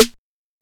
snare 7.wav